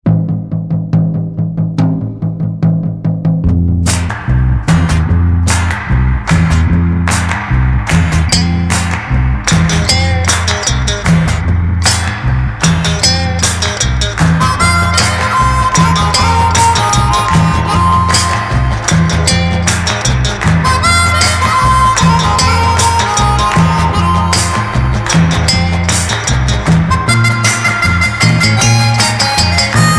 Rock instrumental